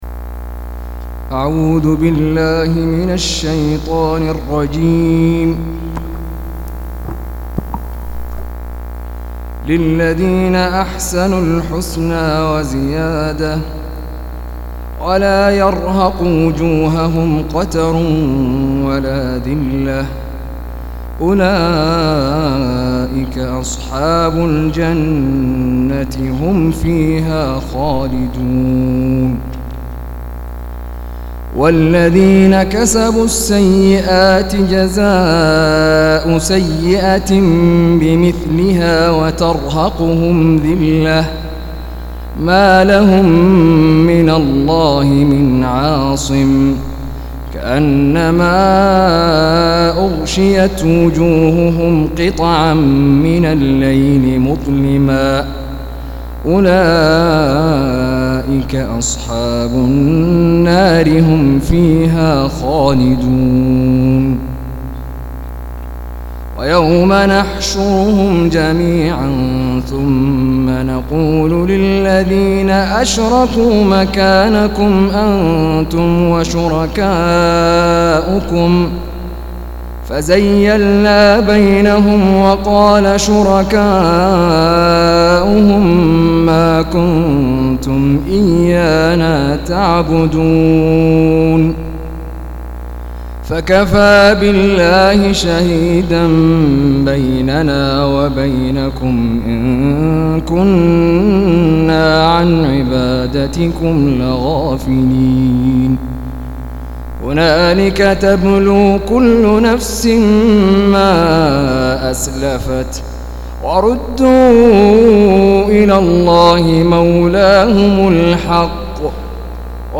202- عمدة التفسير عن الحافظ ابن كثير رحمه الله للعلامة أحمد شاكر رحمه الله – قراءة وتعليق –